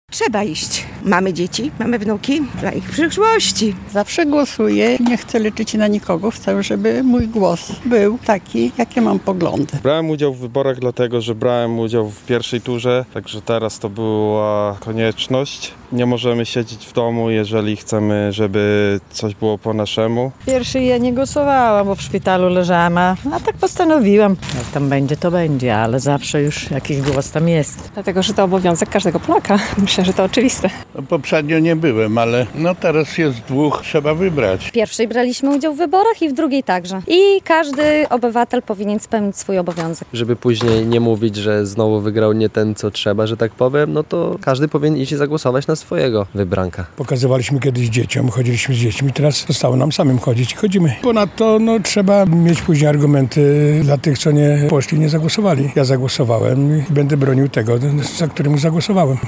Dlaczego suwalczanie biorą udział w wyborach prezydenckich? - relacja